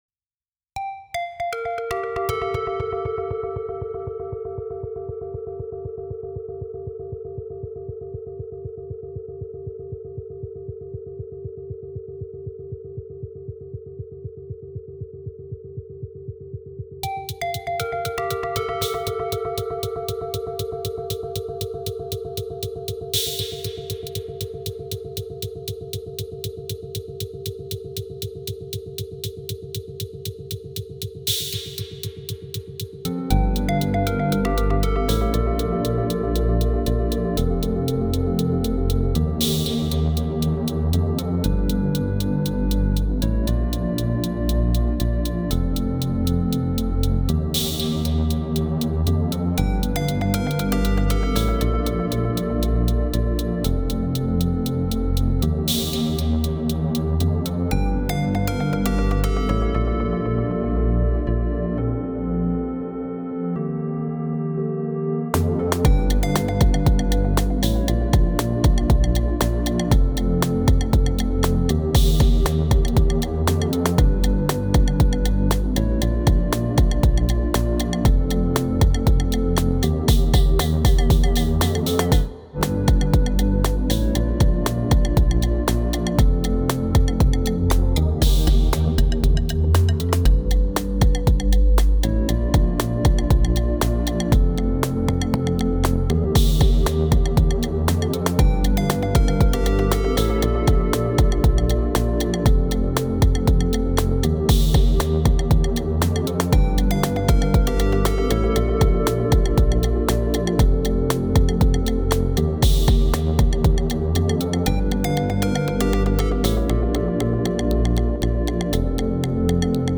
i wanted to upload a couple of older Cycles jams on here, just for fun :slight_smile: